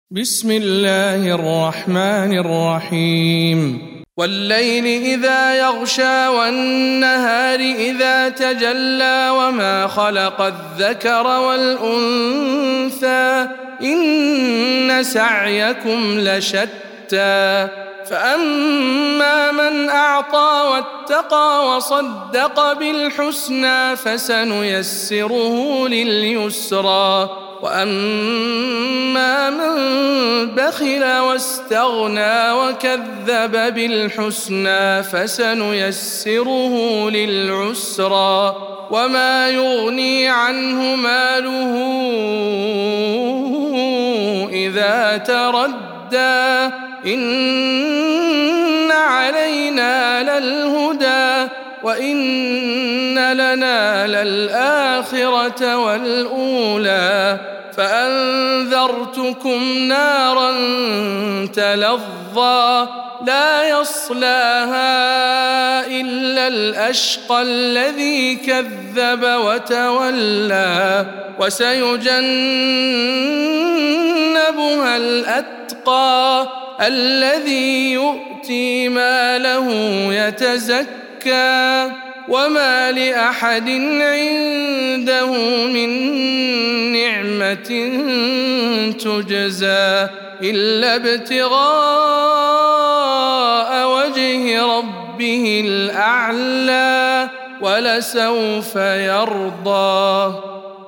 سورة الليل - رواية ابن ذكوان عن ابن عامر